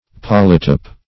polytype - definition of polytype - synonyms, pronunciation, spelling from Free Dictionary
Polytype \Pol"y*type\, n. [Poly- + -type: cf. F. polytype, a.]